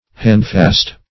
handfast \hand"fast`\, a.